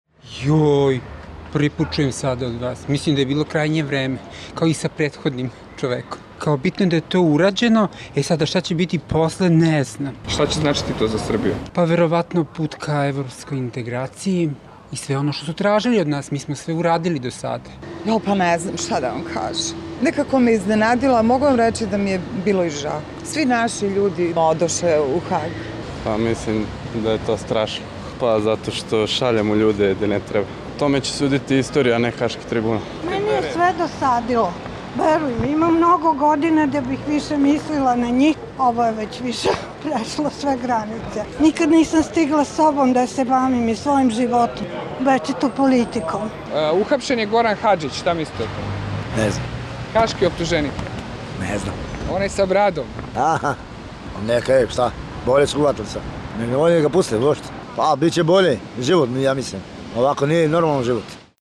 Anketa o hapšenju Gorana Hadžića